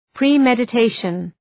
Προφορά
{prı,medə’teıʃən} (Ουσιαστικό) ● προμελέτη